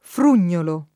frugnolo [ frun’n’ 0 lo o fr 2 n’n’olo ]